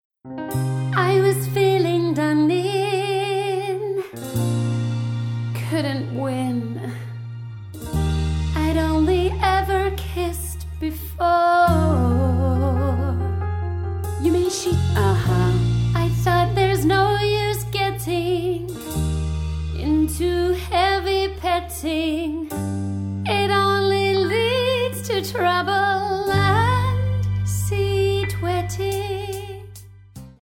Tonart:C-C# Multifile (kein Sofortdownload.
Die besten Playbacks Instrumentals und Karaoke Versionen .